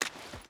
Water Run 3.wav